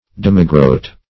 Demigroat \Dem"i*groat`\, n.